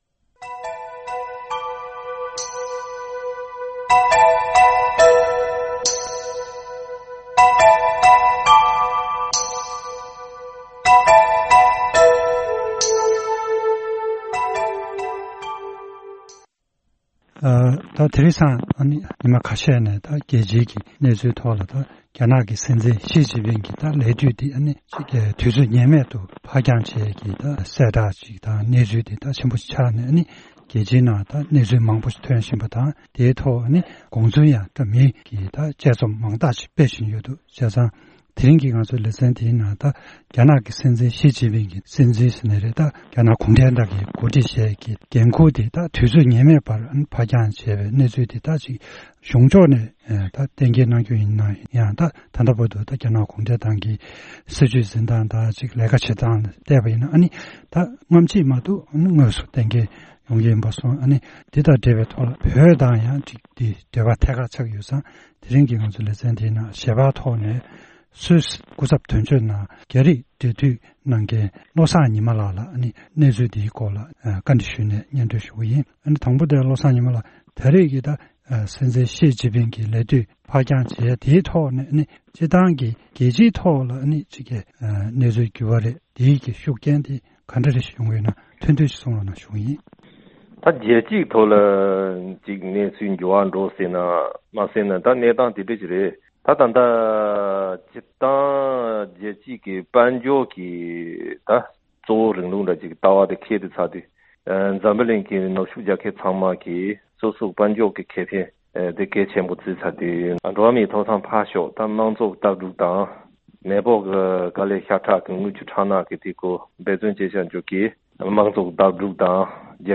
རྒྱ་ནག་སྲིད་འཛིན་གྱི་ལས་དུས་ཕར་འགྱང་དང་འབྲེལ་བའི་ཐད་གླེང་མོལ།
སྒྲ་ལྡན་གསར་འགྱུར།